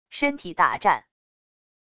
身体打颤wav音频